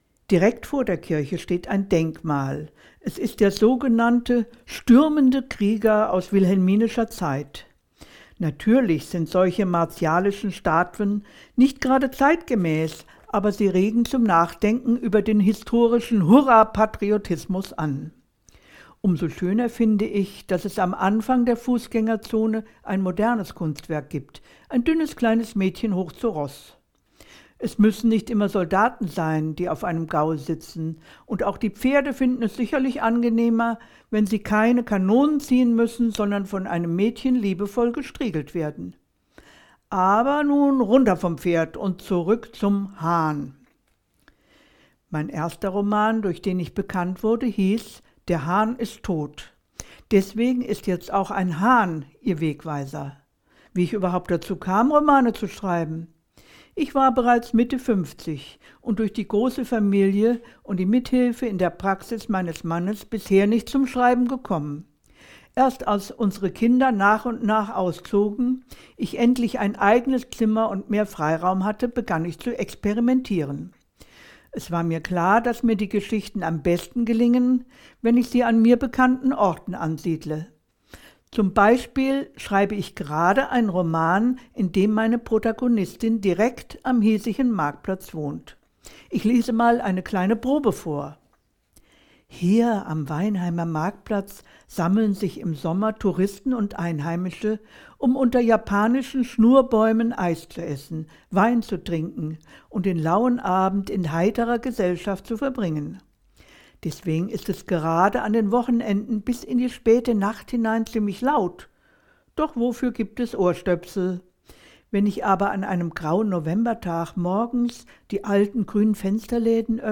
An 13 Stationen in der Innenstadt können Besucher über einen QR-Code mit dem Smartphone die Stimme der Autorin anfordern. In ihrem ganz eigenen Stil und mit einem augenzwinkernd-subtilen Humor führt sie dann den Begleiter von Ort zu Ort.
Ingrid Noll plaudert über die Kunstwerke und Denkmale der Stadt, wie sie zum Schreiben kam und über ihr Erstlingswerk. Sie liest einen ersten Absatz aus ihrem noch unveröffentlichten neuen Roman.